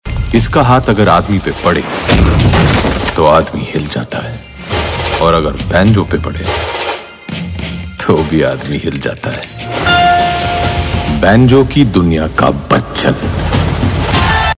Dialogue Mp3 Tone